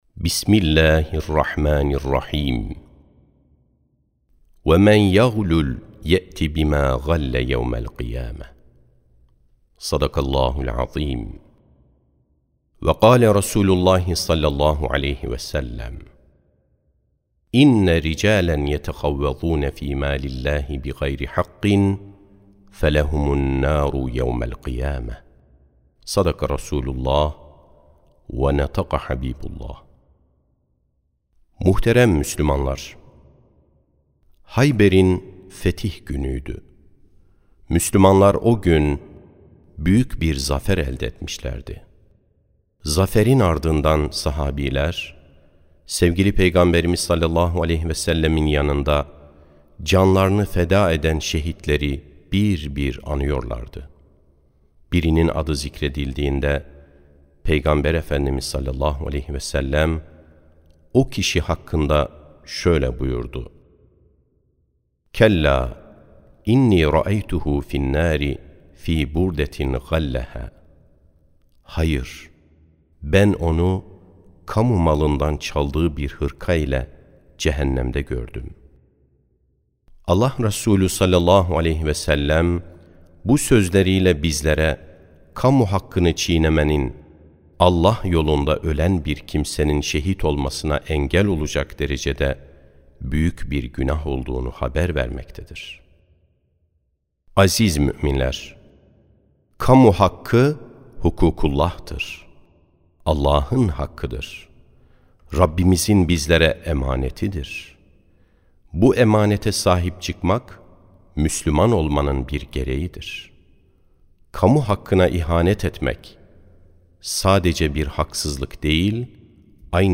27.06.2025 Cuma Hutbesi: Kamu Hakkı Dokunulmazdır (Sesli Hutbe, Türkçe, İngilizce, Rusça, İspanyolca, Fransızca, Arapça, İtalyanca, Almanca)
Sesli Hutbe (Kamu Hakkı Dokunulmazdır).mp3